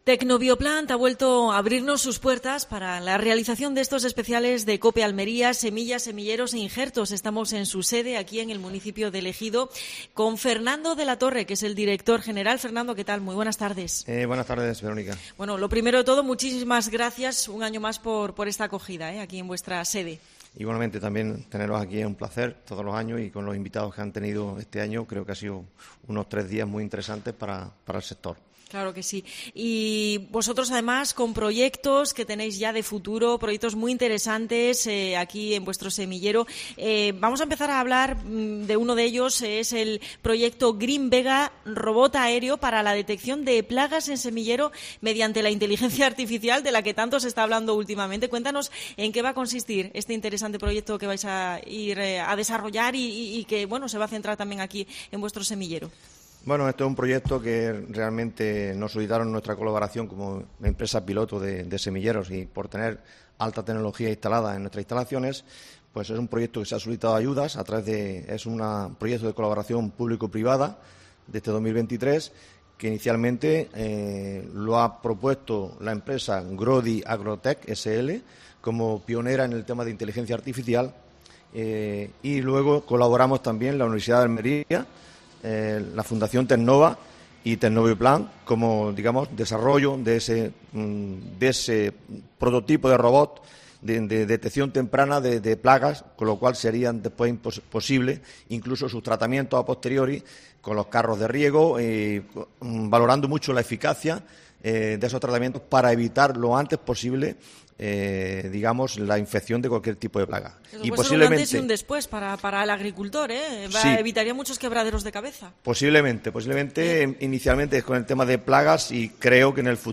Programación especial de COPE Almería desde Tecnobioplant.